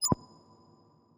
MenuClick.wav